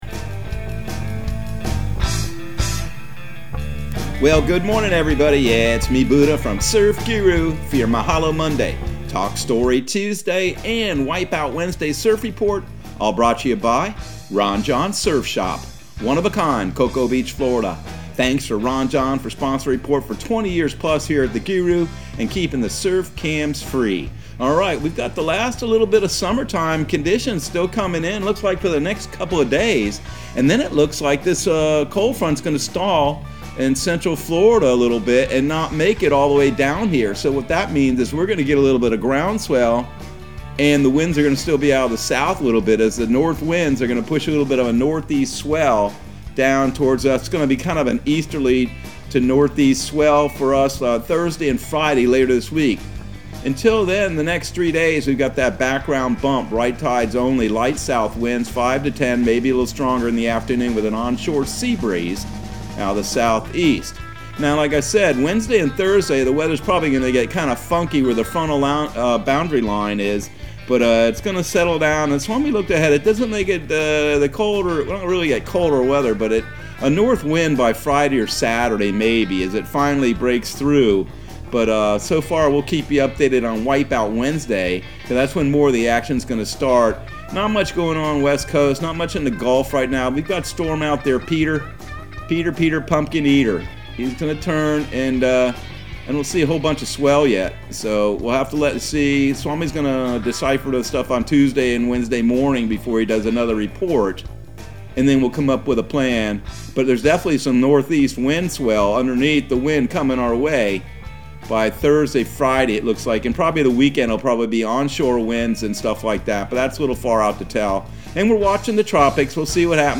Surf Guru Surf Report and Forecast 09/20/2021 Audio surf report and surf forecast on September 20 for Central Florida and the Southeast.